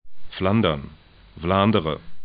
'flandɐn